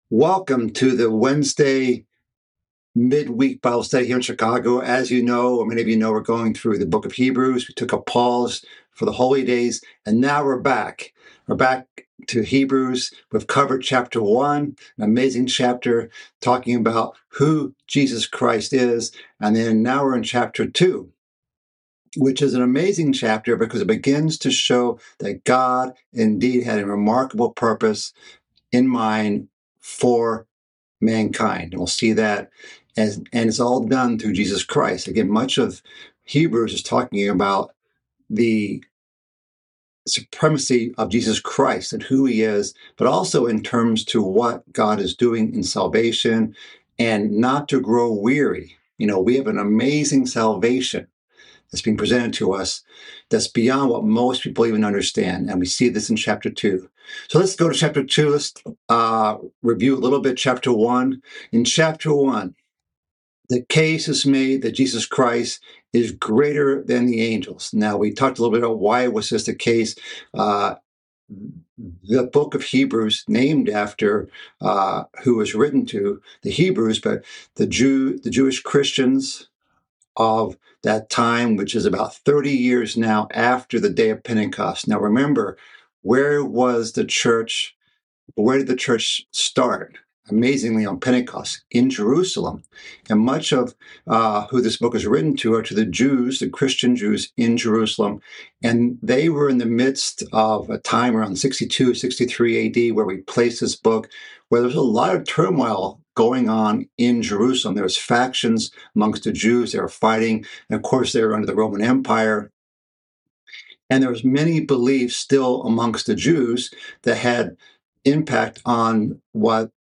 Bible Study - Hebrews Part 6 - 2:1-10
The sixth part in a series of mid-week Bible studies, covering the book of Hebrews. This session begins the second chapter of Hebrews.